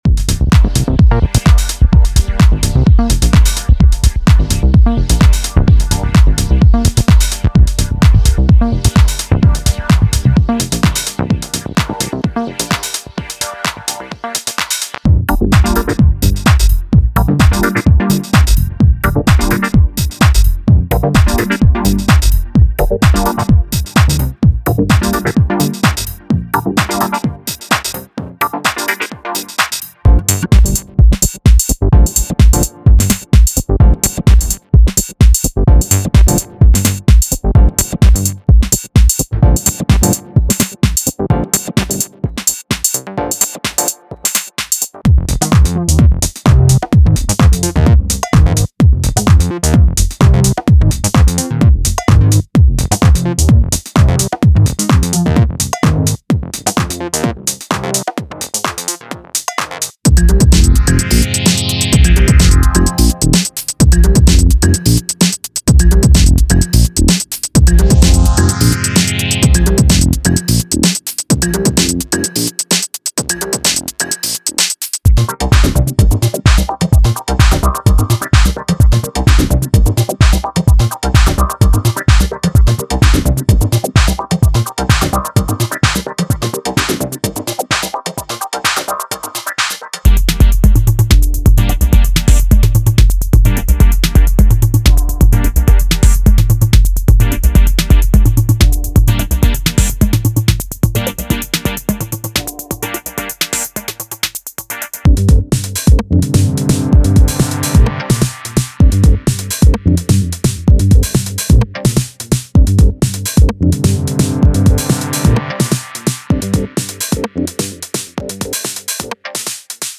パーカッションは流動的でエレガントな動きを生み出します。
ベースラインはディープでロール感があり、催眠的なグルーヴを形成。
Genre:Minimal Techno
67 Drum loops (Full, Kick, Hihat, Perc, Snare)